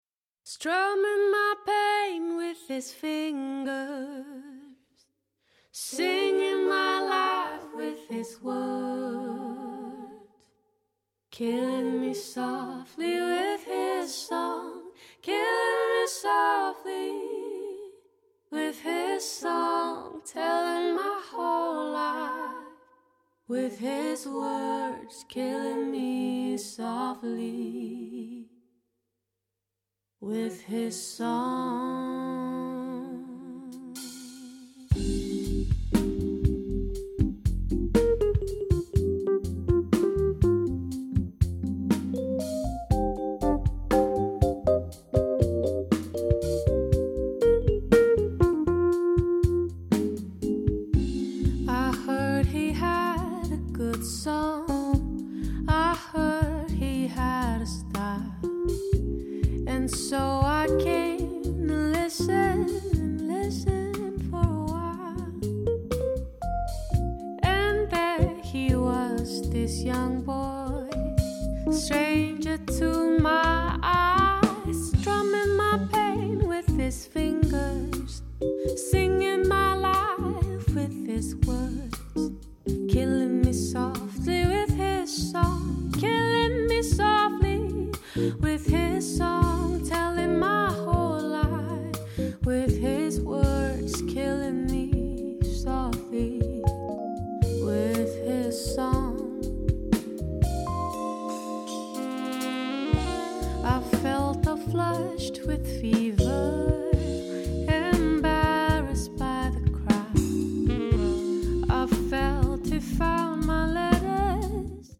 R&B
歌聲甜美多變、展現高超唱功外
通透的女聲、紮實的鼓聲、定位精確的空間感，都讓發燒友欲罷不能，一聽再聽！